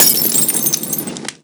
molotov_explode_REPLACEME.wav